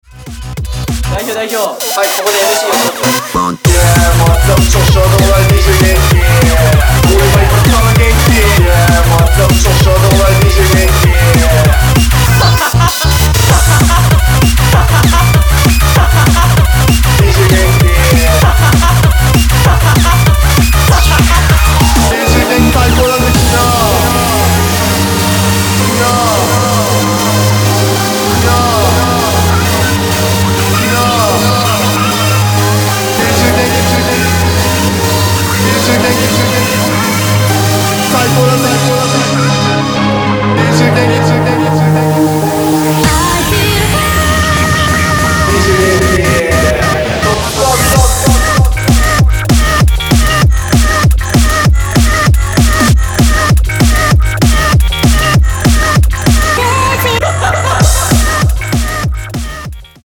クロスフェードデモ
Vocal